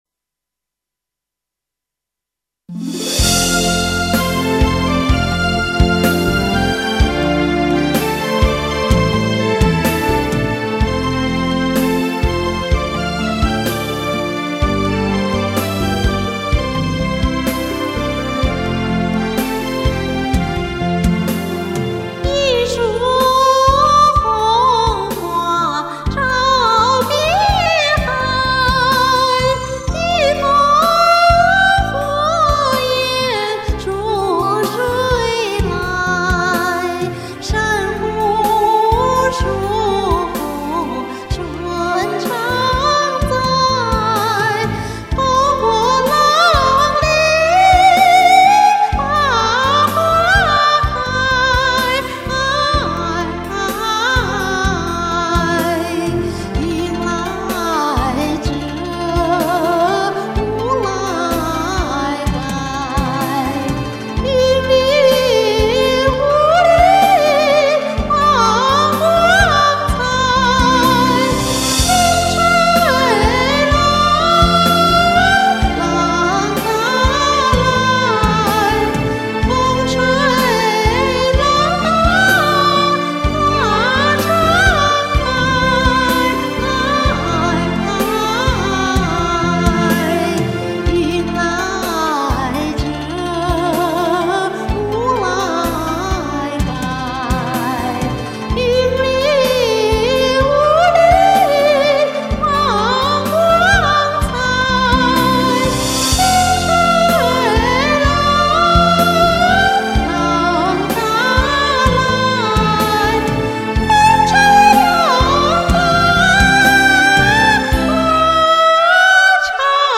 經典老歌
我唱這首歌，是想練練高音，因為我常唱和聲歌曲，不能只練中聲區，高音、低音都得練。
我這個伴奏害我！